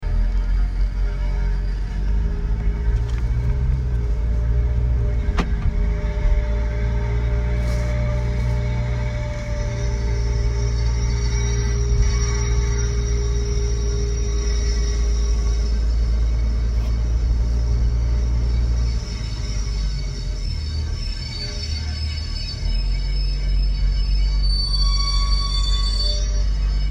Das Soundfile stammt von den letzten Metern Fahrt mit meinem Auto mit 10 kmh. Gerade 200 m vor Zuhause fingen die Geräusche an.
Am Ende des 20 sec langen Soundfiles habe ich angehalten. Klingt wie wenn ein alter Zug in den Bahnhof einläuft...